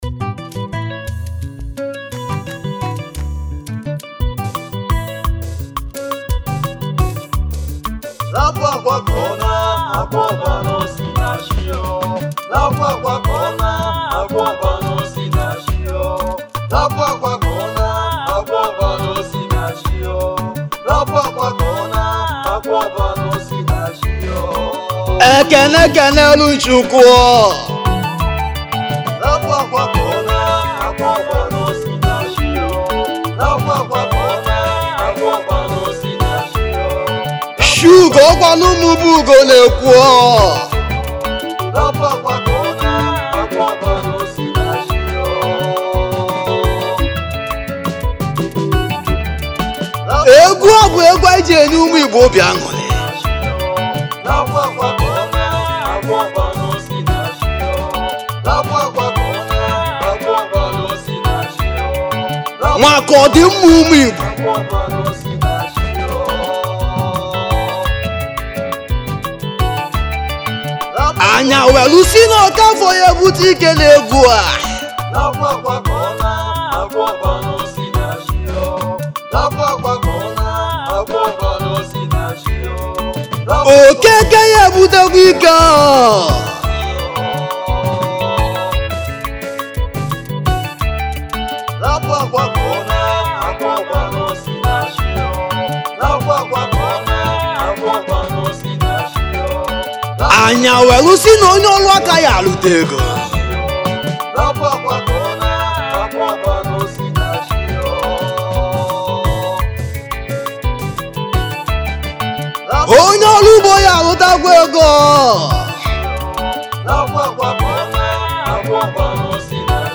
igbo highlife
Highlife Traditional